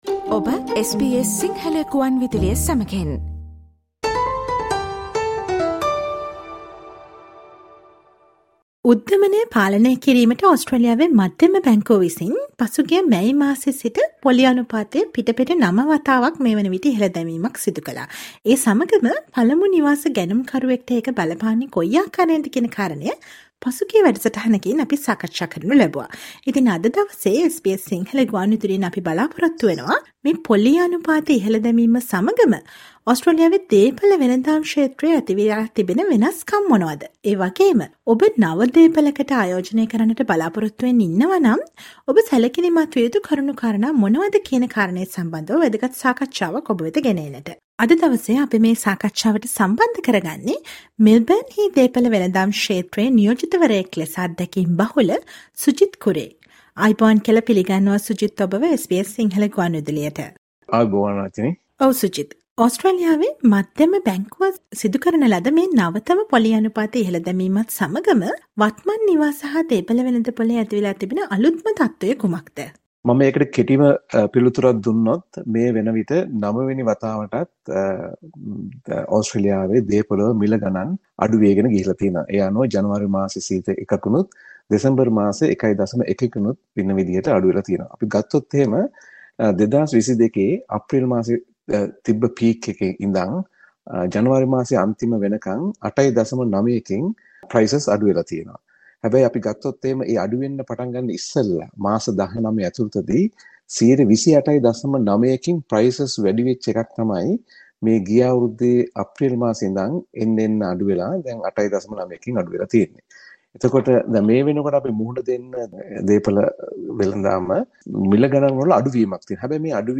Listen to the SBS sinhala radio interview